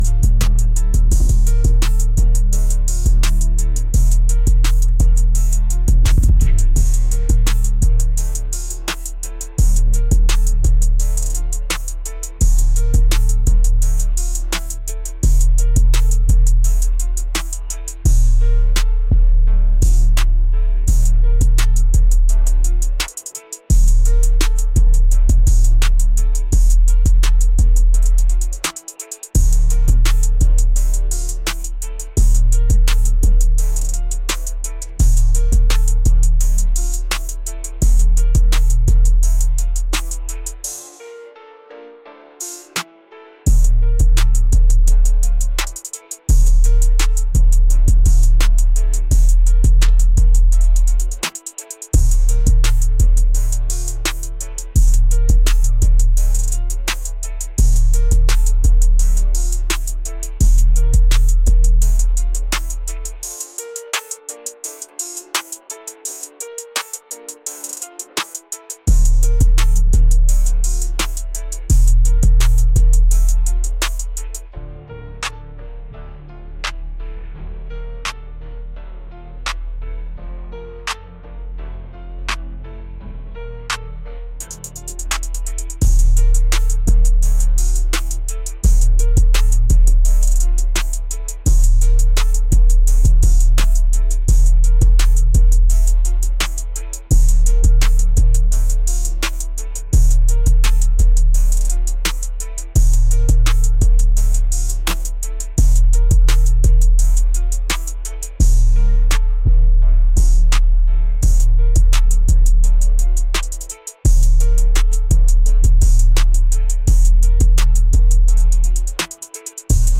aggressive | intense